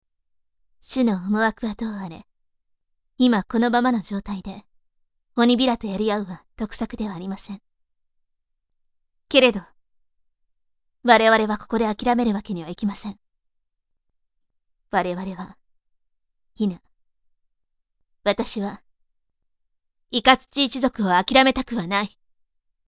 【サンプルセリフ】
そこに、透明感のあるお声のイメージもプラスして、凛とした雰囲気で設定してみました。